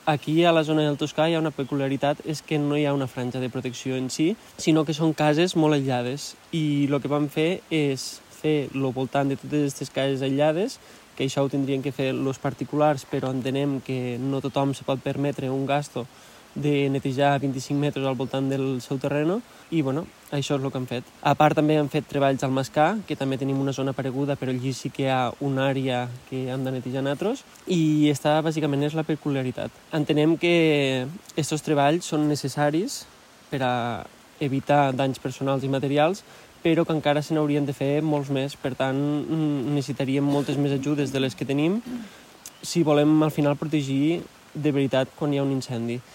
L’alcalde d’Alfara de Carles, Jordi Forné ha agraït el suport del COPATE en les tasques de neteja forestal però ha reclamat més suport de les actuacions superiors…